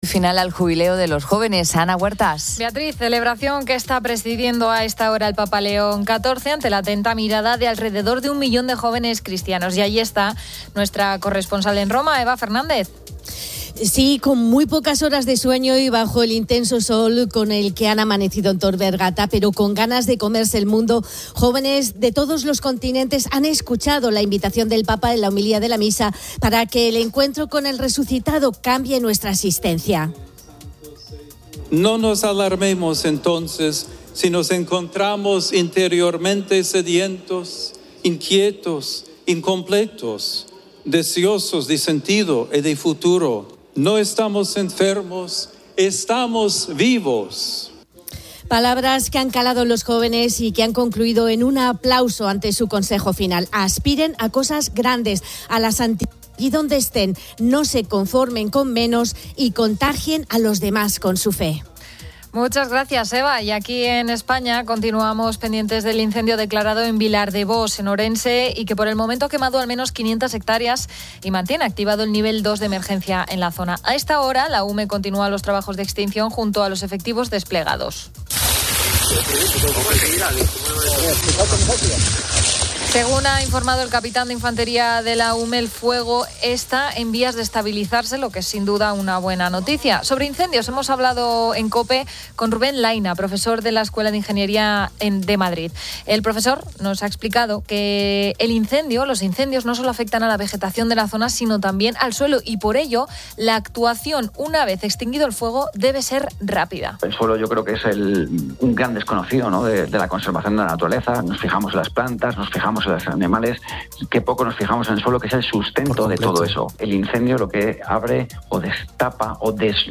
Hemos entrevistado a jóvenes que utilizan su verano para marcar la diferencia reconstruyendo iglesias Leer más User Admin 03 ago 2025, 11:07 - 60:00 MIN Descargar Facebook Twitter Whatsapp Telegram Enviar por email Copiar enlace